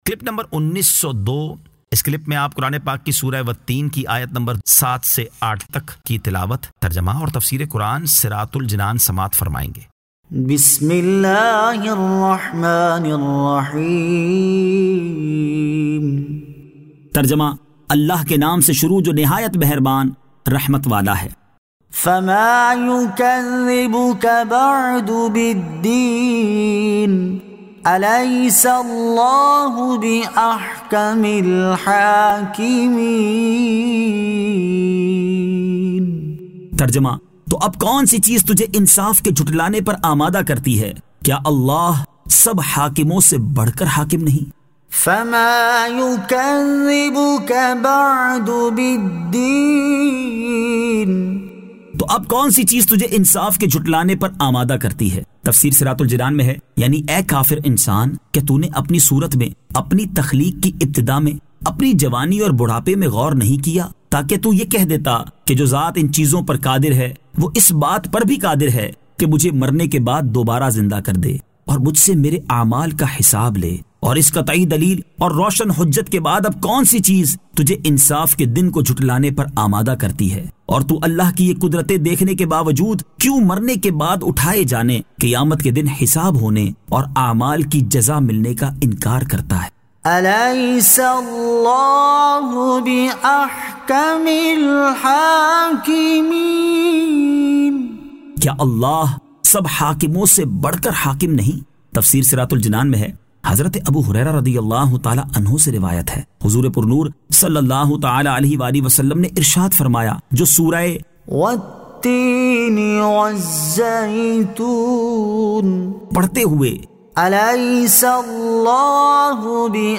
Surah At-Teen 07 To 08 Tilawat , Tarjama , Tafseer